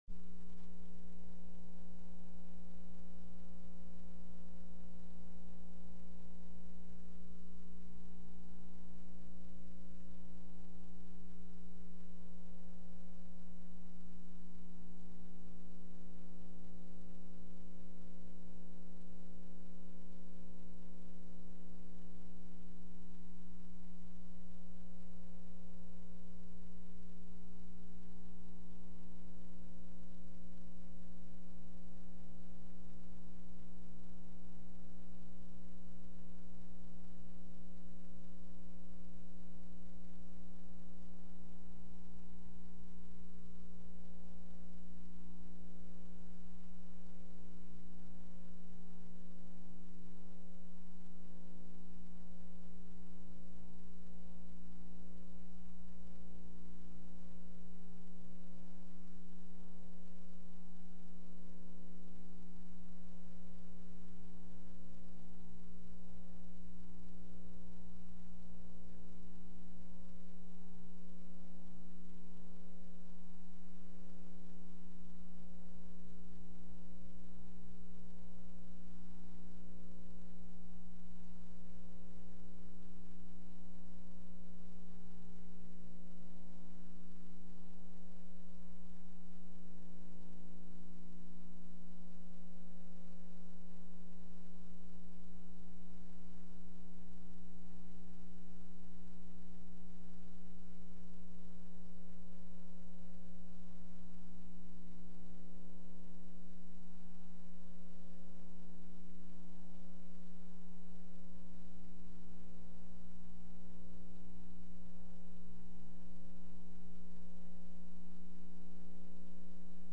01/21/2005 01:30 PM House FINANCE